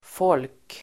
Uttal: [fål:k]